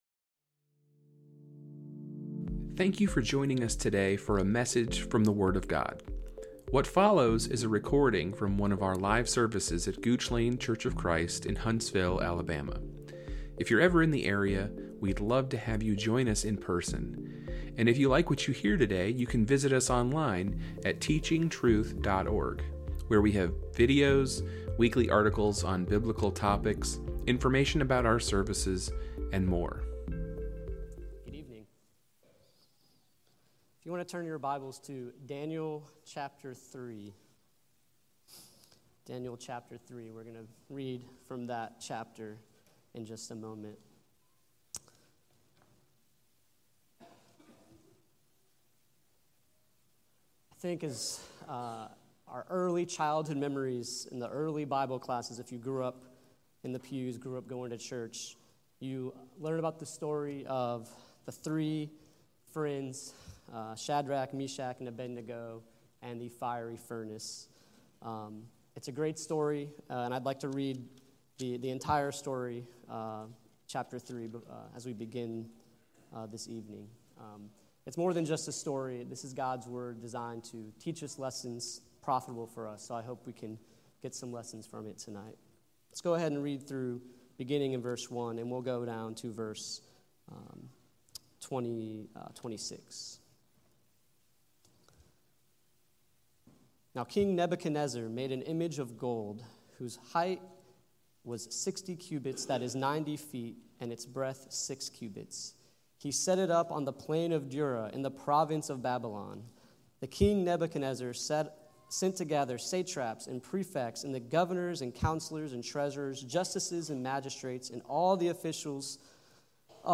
A sermon given on April 6, 2025.